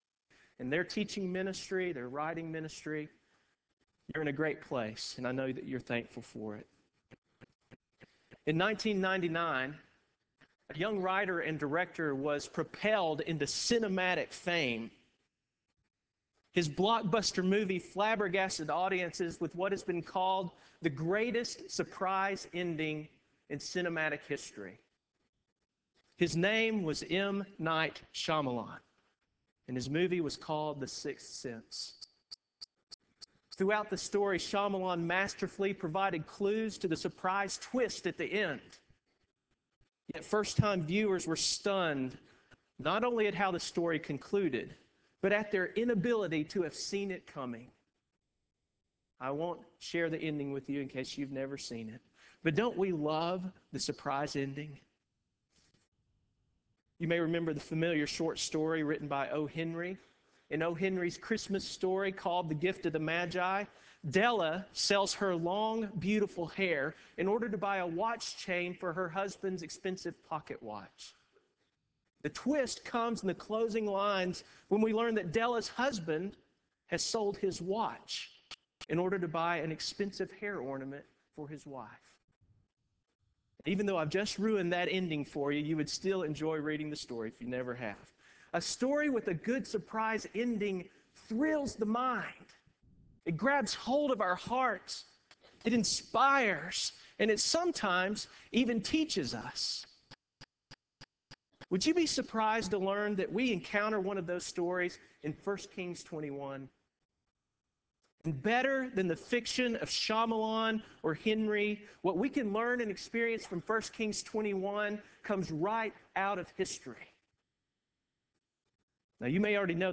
Address: "The Surprise Ending," from 1 Kings 21